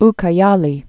(kä-yälē)